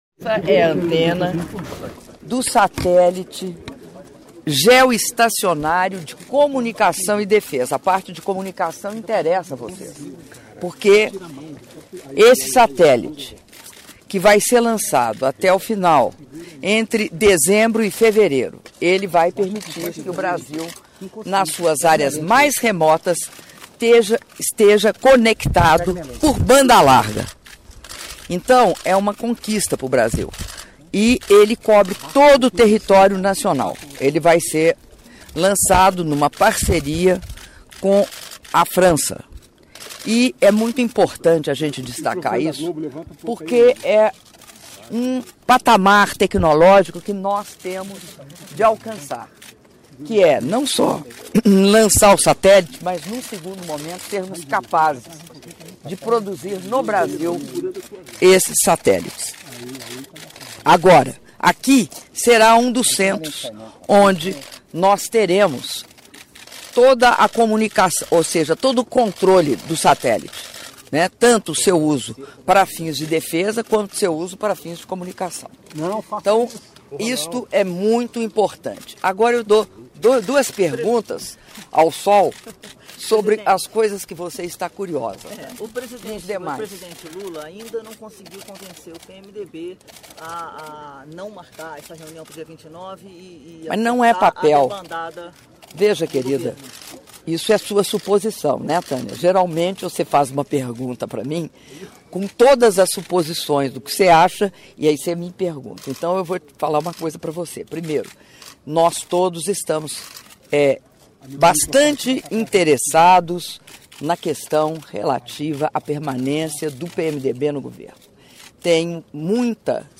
Áudio da entrevista da Presidenta da República, Dilma Rousseff, após Visita às obras de infraestrutura de solo para operação do Satélite Geoestacionário de Defesa e Comunicações Estratégicas, do Centro de Operações Espaciais- COPE - Brasília/DF (8min27s)